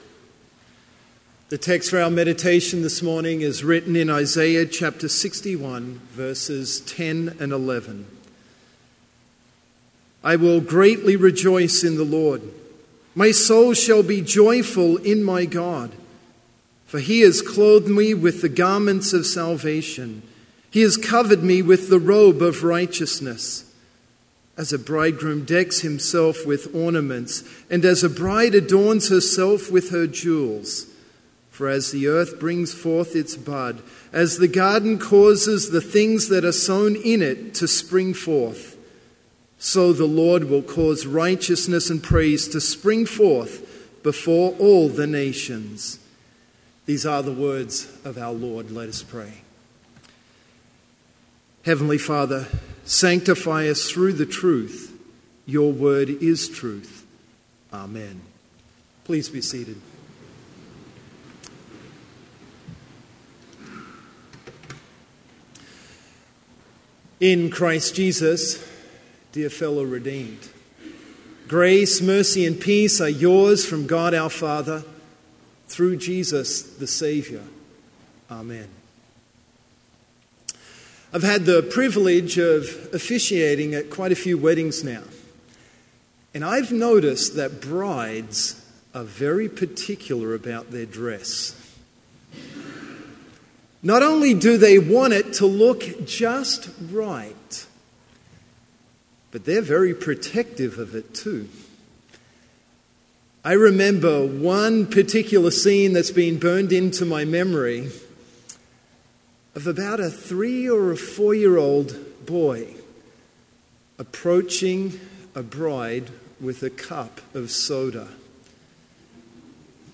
Complete service audio for Chapel - February 6, 2017